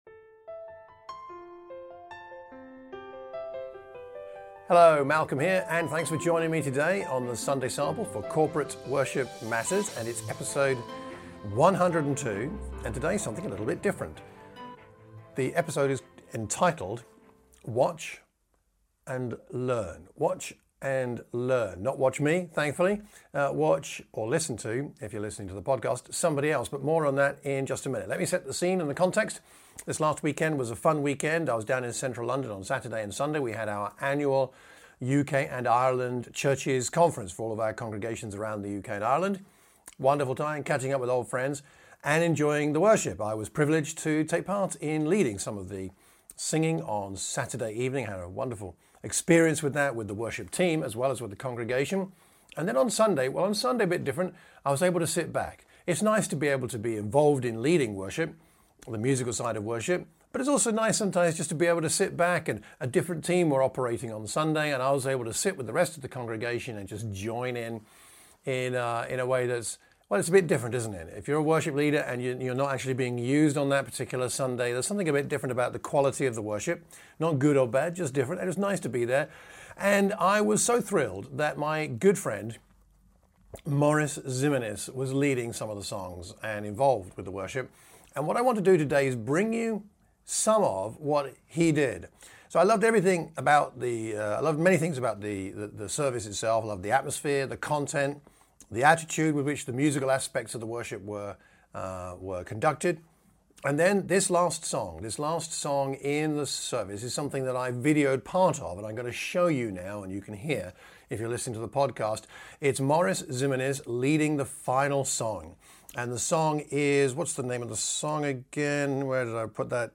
I spent Saturday and Sunday last weekend at our UK/Ireland annual conference in central London.